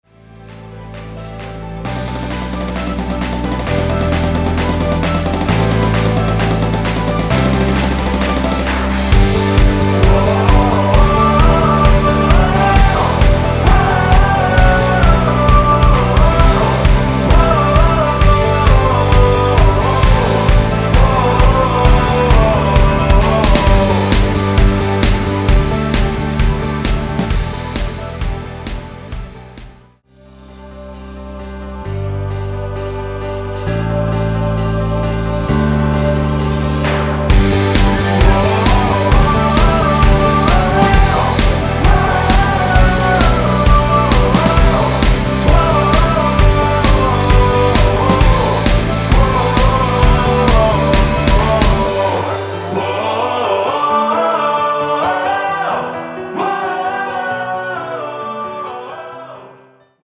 원키에서(-2)내린 코러스 포함된 MR입니다.(미리듣기 확인)
앞부분30초, 뒷부분30초씩 편집해서 올려 드리고 있습니다.
중간에 음이 끈어지고 다시 나오는 이유는